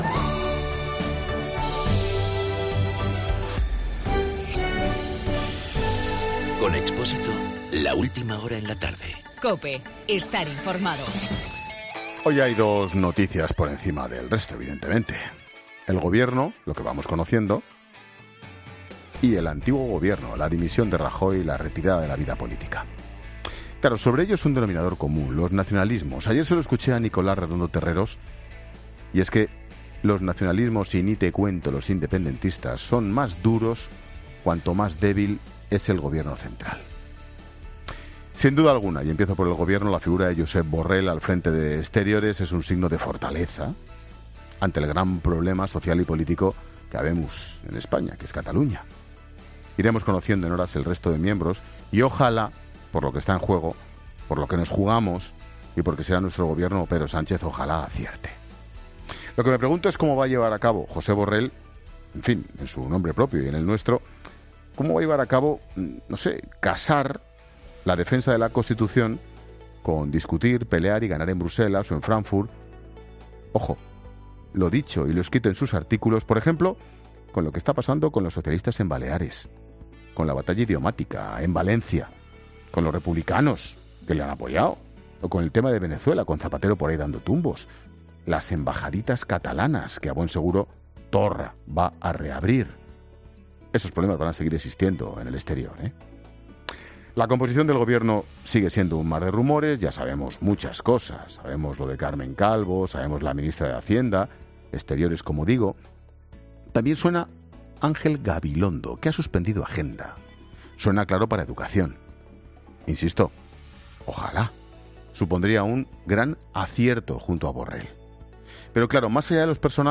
Monólogo de Expósito
El comentario de Ángel Expósito siguiendo los nombramientos de Sánchez para sus ministerios.